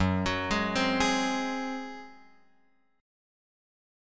F#sus2b5 chord {2 x 4 1 1 4} chord